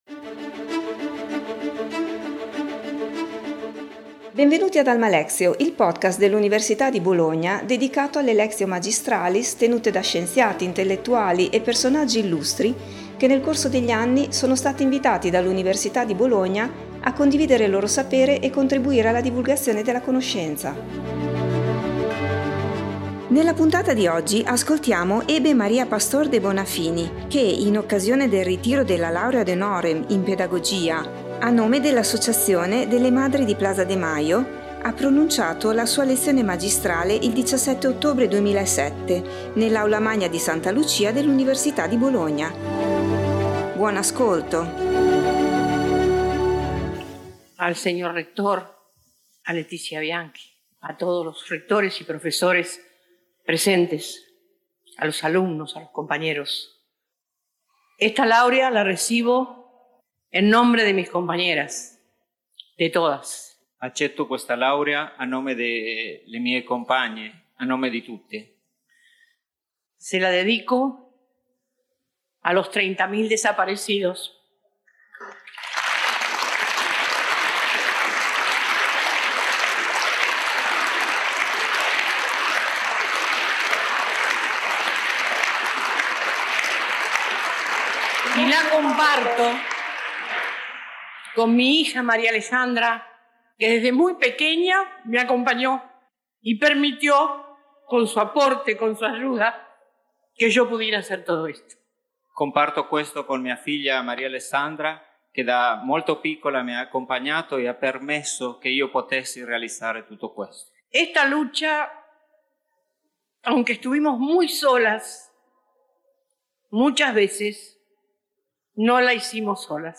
Hebe Maria Pastor de Bonafini ha pronunciato la sua lectio magistralis il 17 ottobre 2007 nell’Aula Magna di Santa Lucia dell’Università di Bologna in occasione del ritiro della laurea ad honorem in Pedagogia a nome dell'Asociaciòn Madres de Plaza de Mayo.